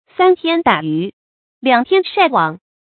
注音：ㄙㄢ ㄊㄧㄢ ㄉㄚˇ ㄧㄩˊ ，ㄌㄧㄤˇ ㄊㄧㄢ ㄕㄞˋ ㄨㄤˇ